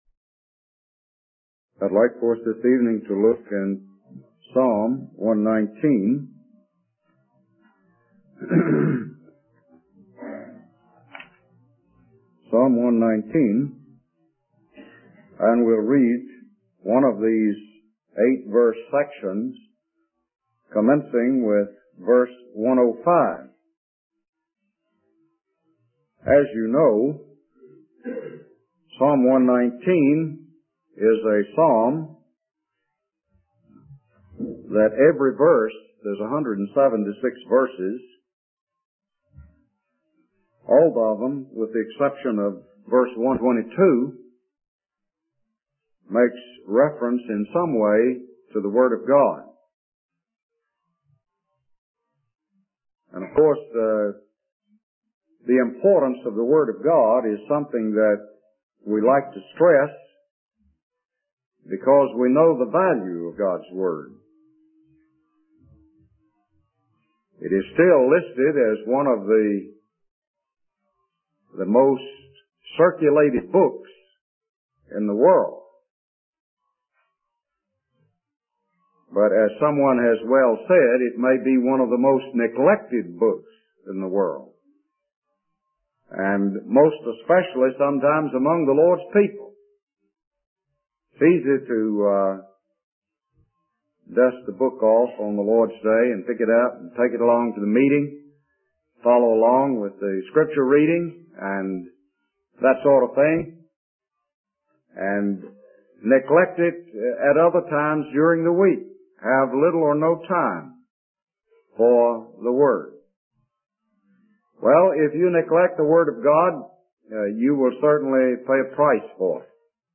In this sermon, the speaker emphasizes the value and importance of knowing and studying the Word of God. He compares the Word of God to a lamp that guides and illuminates our path in life.